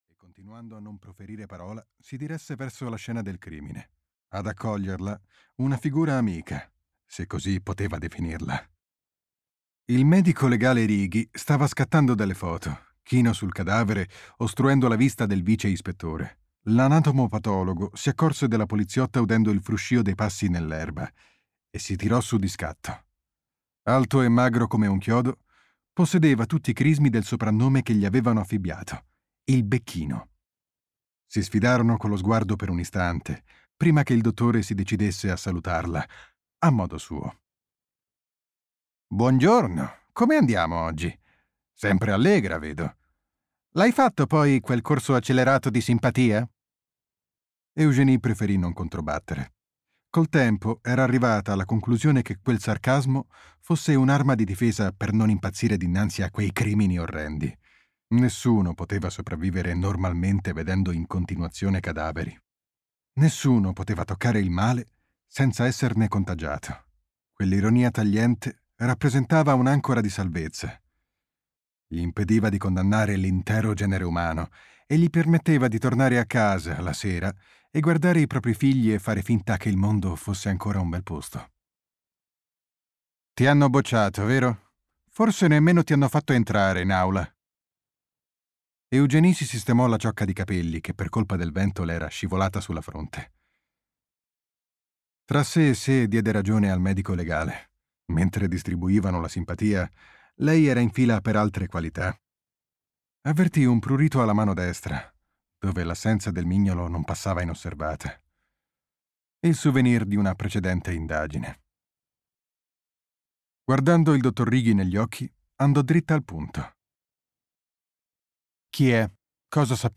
"Nel nero degli abissi" di François Morlupi - Audiolibro digitale - AUDIOLIBRI LIQUIDI - Il Libraio